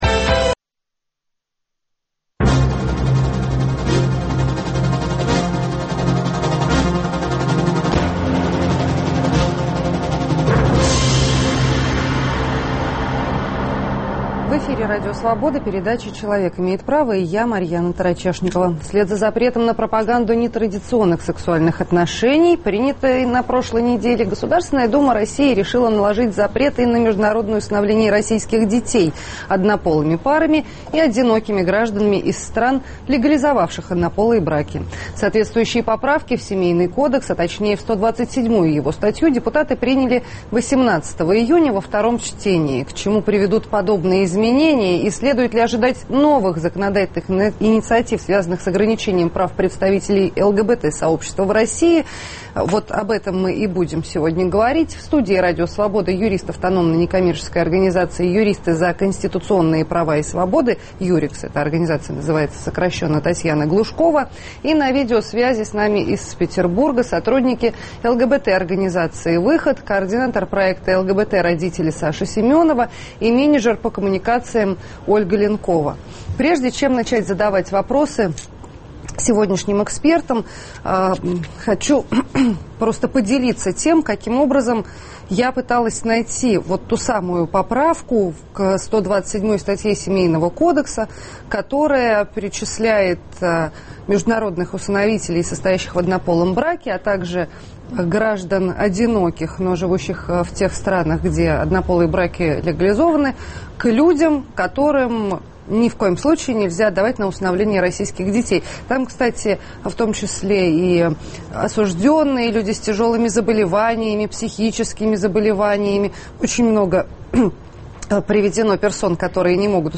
На видеосвязи из Санкт-Петербурга сотрудники ЛГБТ-организации "Выход".